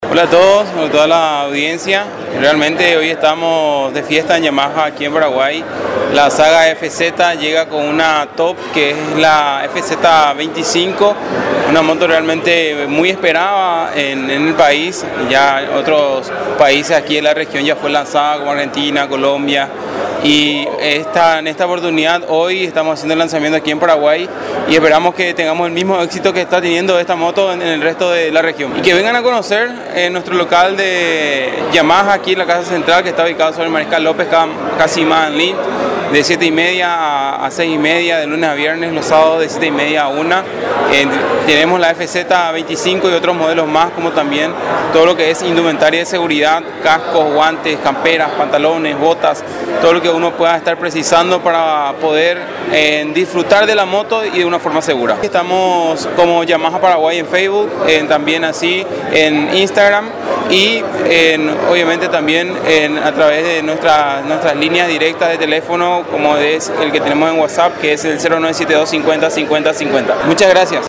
ENTREVISTA-CONTRACORRIENTE-22-02.mp3